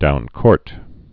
(doun-kôrt)